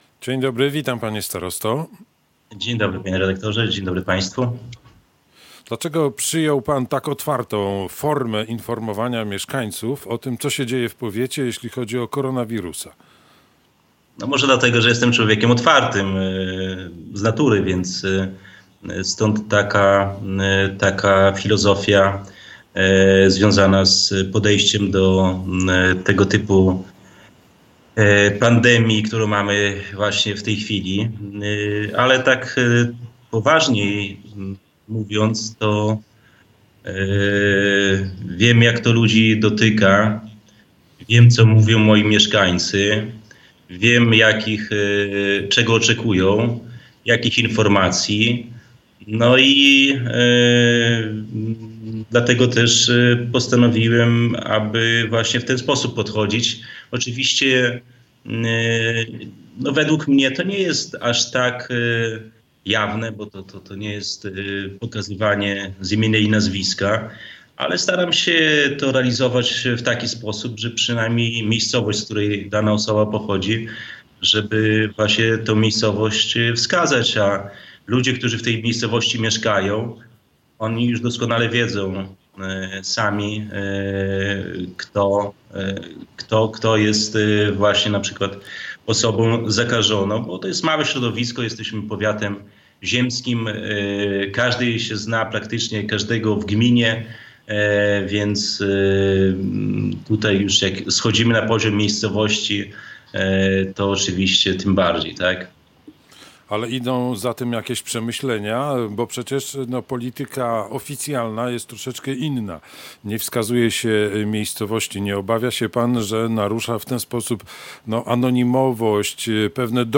starosta wysokomazowiecki
Bogdan Zieliński, screen Skype